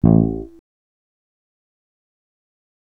A HARMONIC.wav